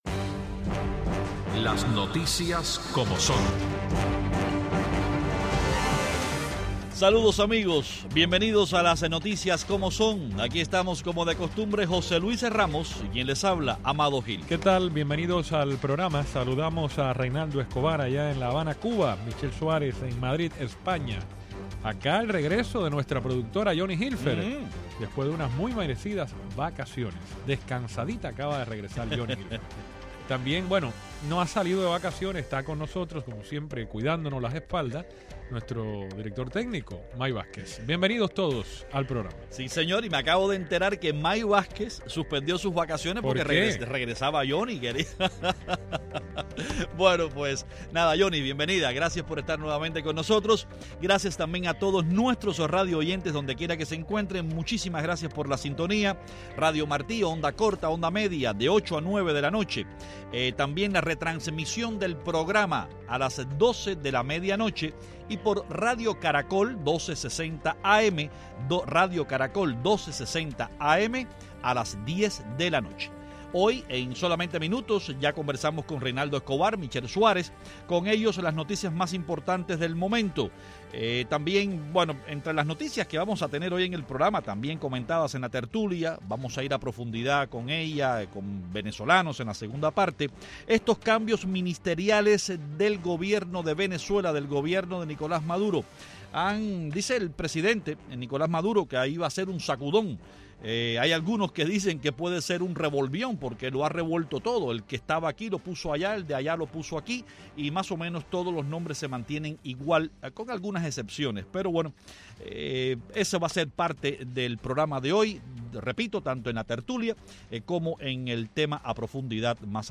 Los periodistas cubanos
desde La Habana
desde Madrid